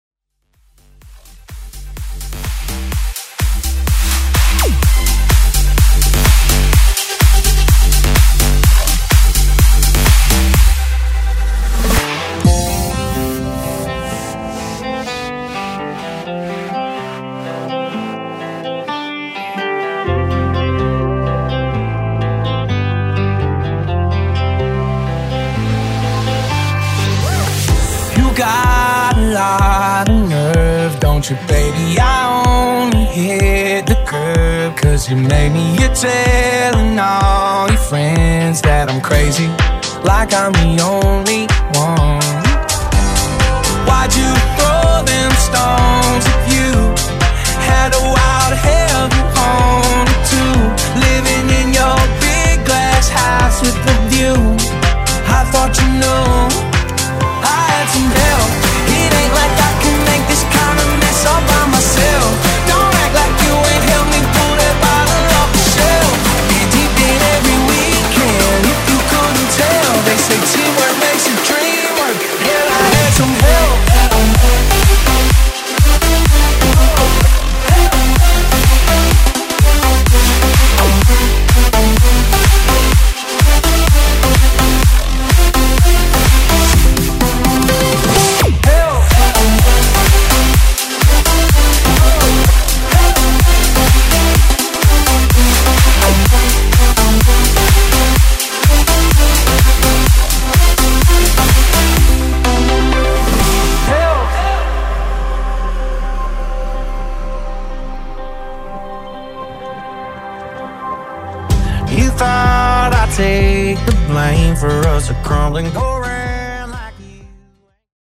Genre: BOOTLEG
Clean BPM: 125 Time